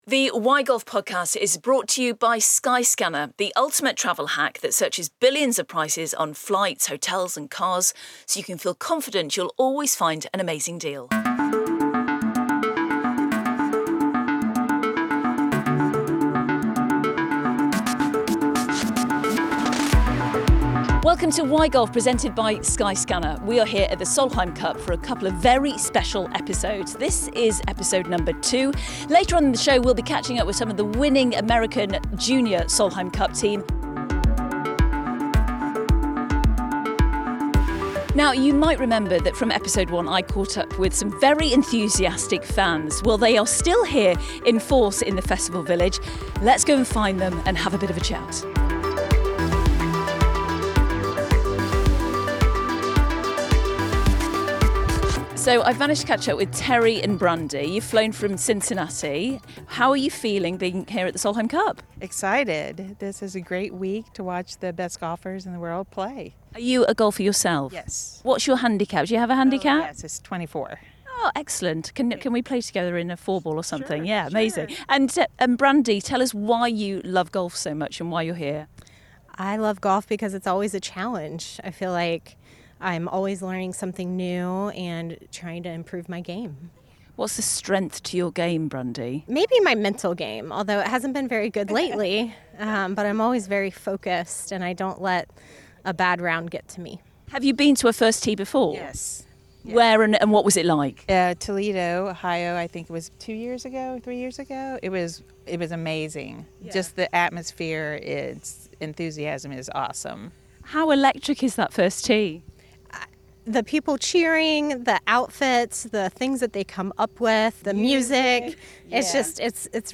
Why Golf is back in Virginia for a second episode from the 2024 Solheim Cup.